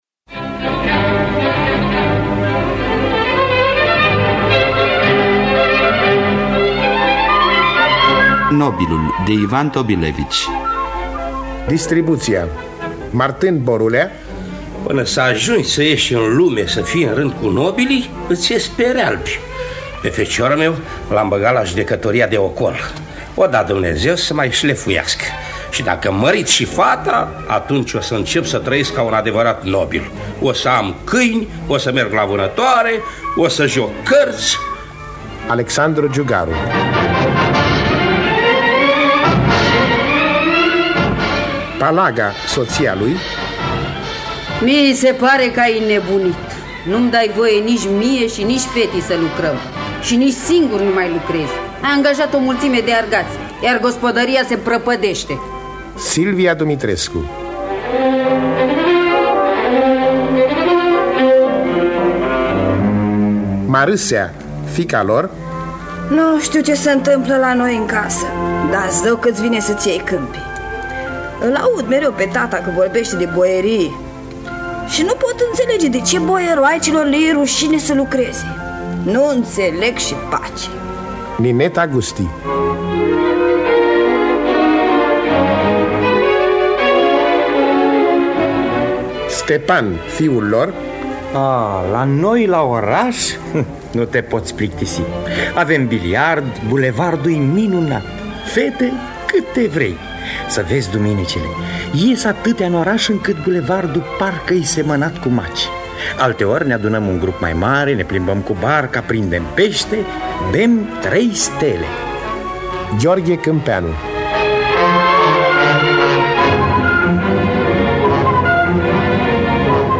Nobilul sau Martin Borulia de Ivan Karpovich Tobilevych – Teatru Radiofonic Online